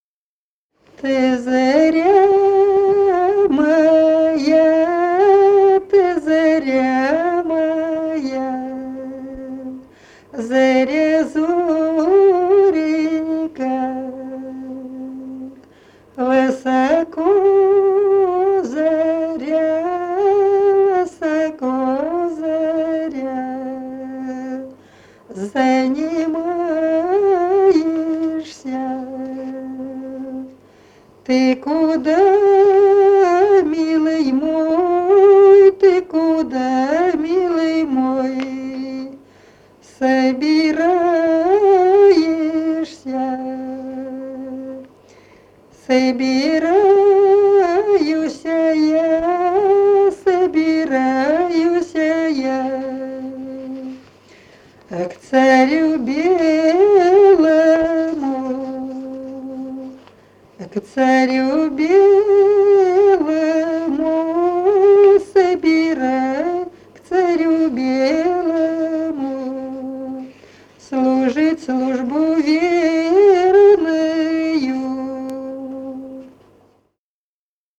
Народные песни Смоленской области